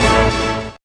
hitTTE68013stabhit-A.wav